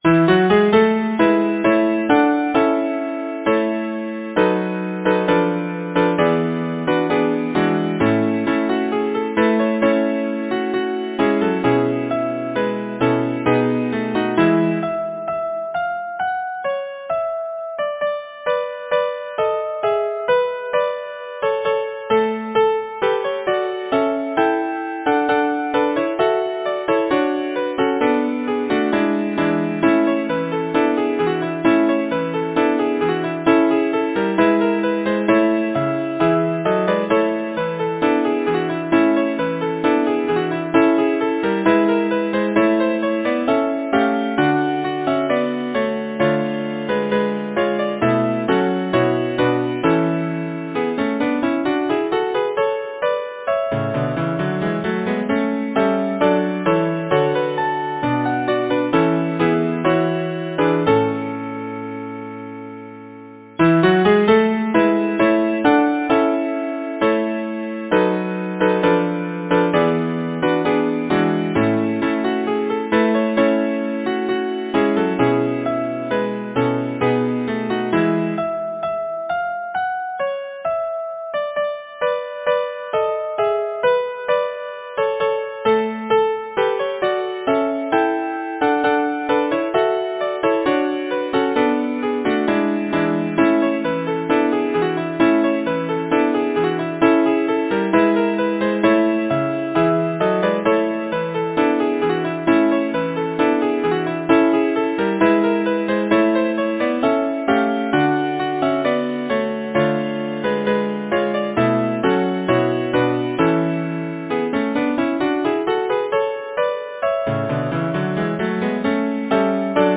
Number of voices: 4vv Voicing: SATB Genre: Secular, Partsong
Language: English Instruments: a cappella or Keyboard